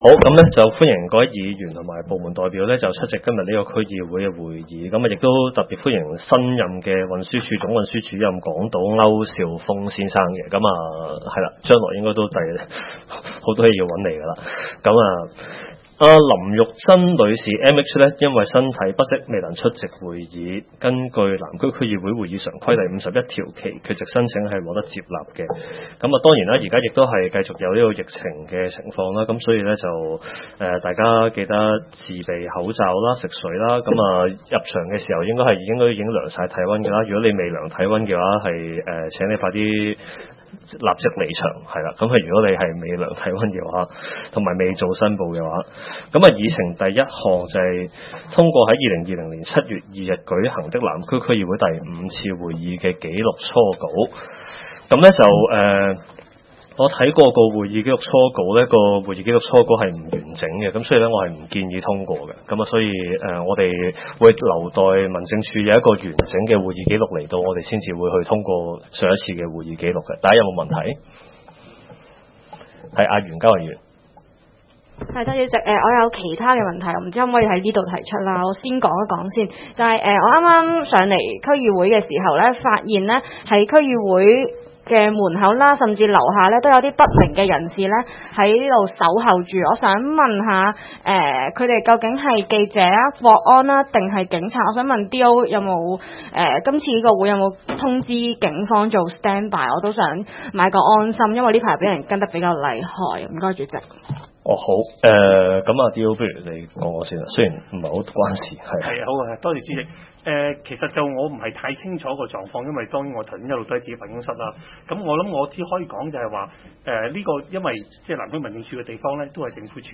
南区区议会大会的录音记录
南区区议会会议室